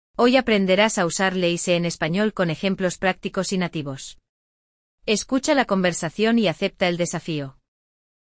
Aprenda alguns usos dos pronomes LE e SE com um diálogo sobre feira!
E eu lanço um desafio para você: ouvir a conversa entre os nativos, interpretar e responder as perguntas!